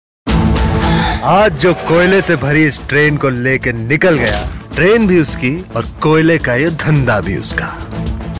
Bollywood Dialogue Tones